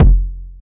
Kicks
CC - Fire Kick.wav